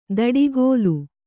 ♪ daḍigōlu